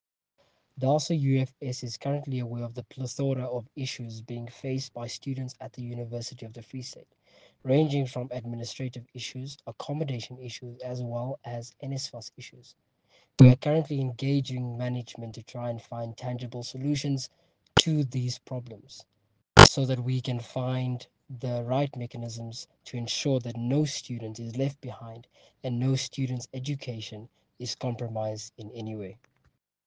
English soundbite